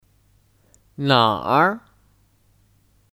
哪儿 Nǎr (Kata tanya): Mana Contoh kalimat:你住哪儿 Nǐ zhù nǎr? (Kamu tinggal dimana?)